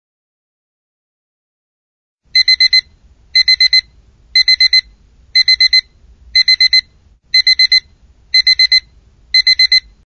1-second-timer1.mp3